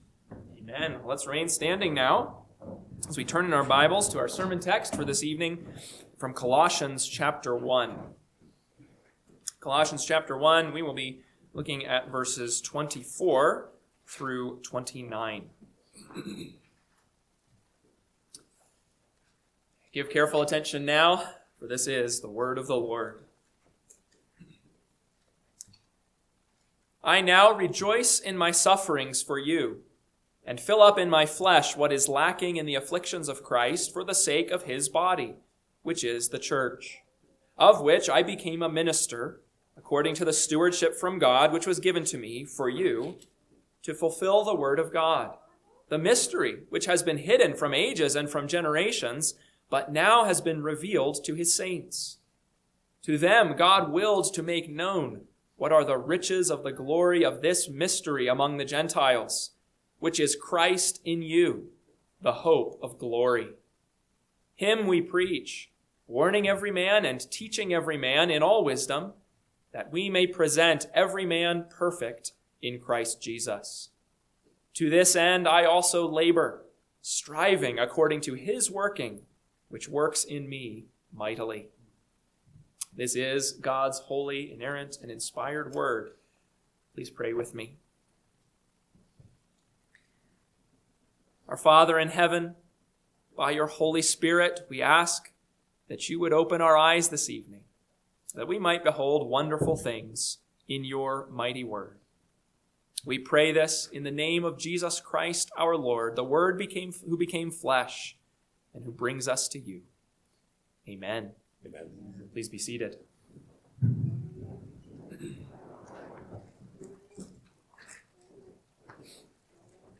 PM Sermon – 2/1/2026 – Colossians 1:24-29 – Northwoods Sermons